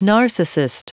Prononciation du mot : narcissist